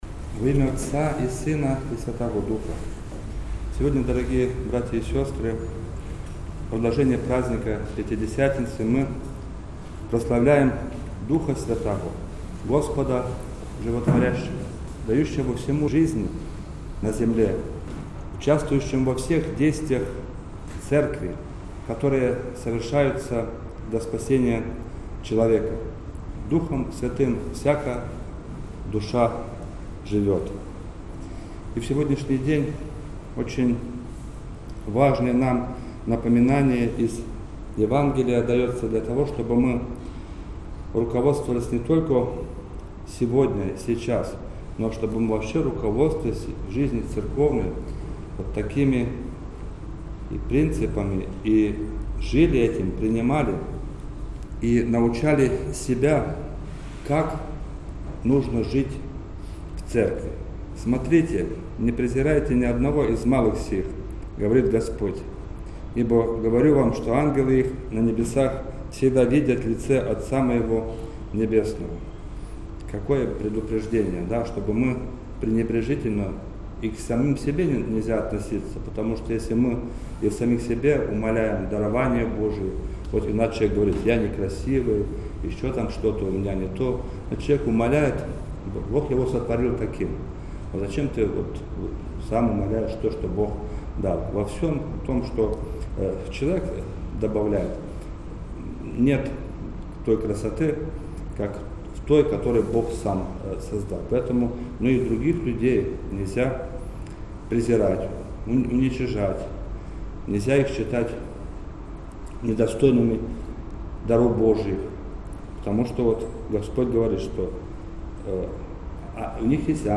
Проповедь
после Уставной службы
Уставная-служба.-День-Святого-Духа.mp3